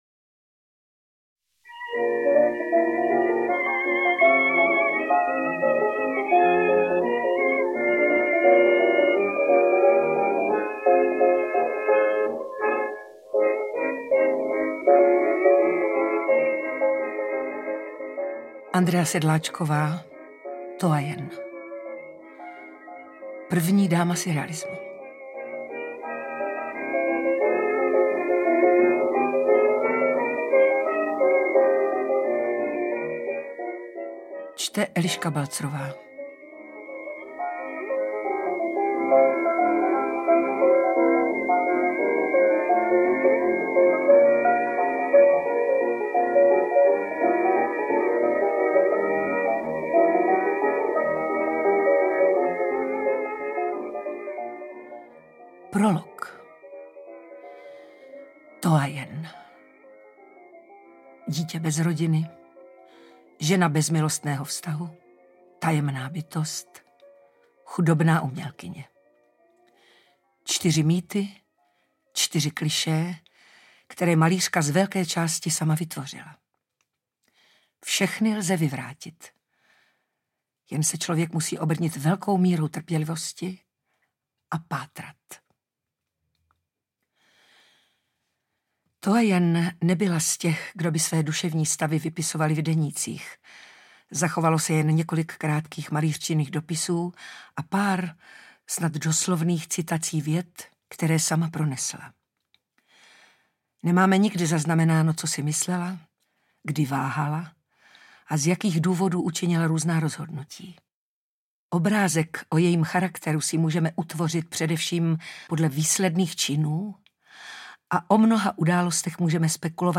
Interpret:  Eliška Balzerová